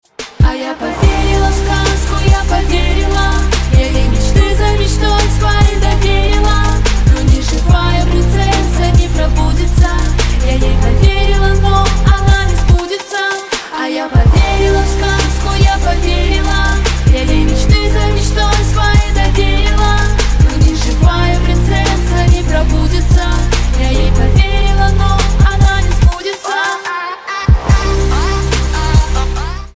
поп
RnB